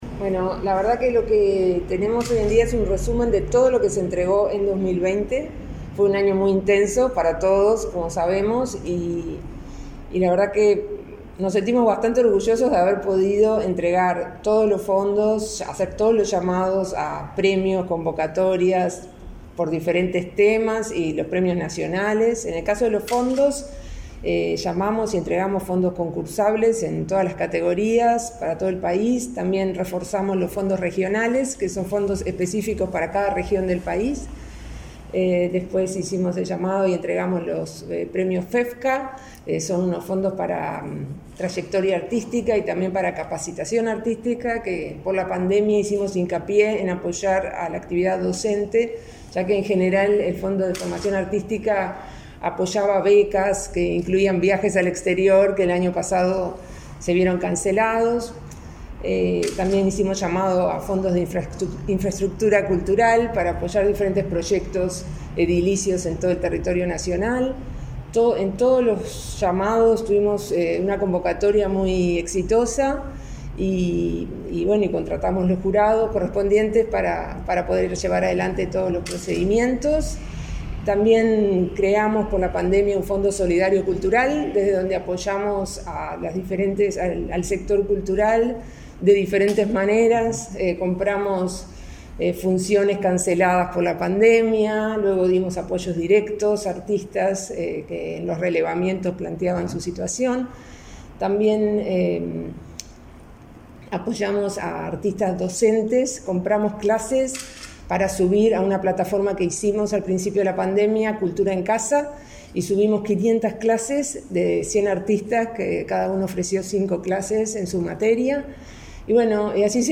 Entrevista a Mariana Waistein, directora nacional de Cultura